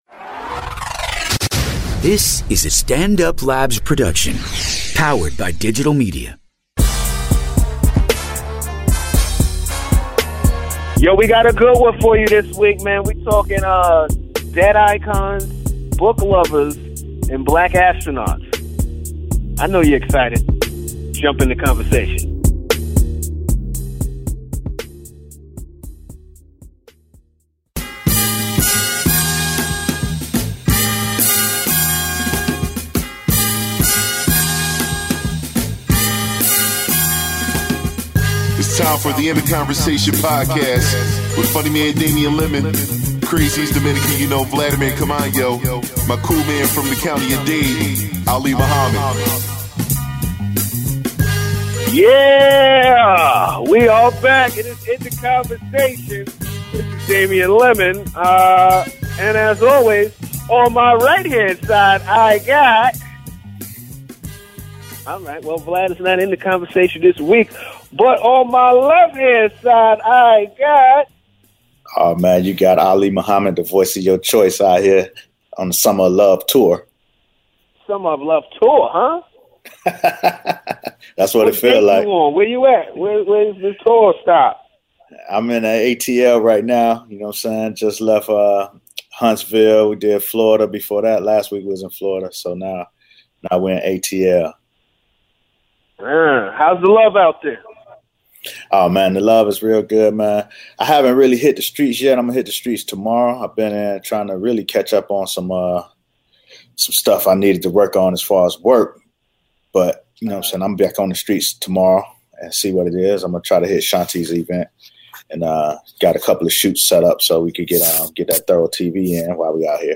host the show from two separate, undisclosed locations